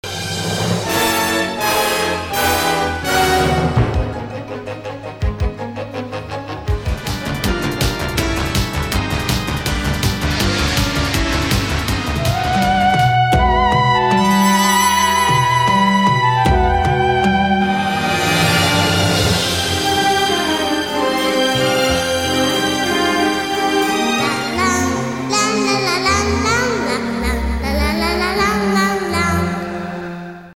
Like music used to soothe gigantic bugs. https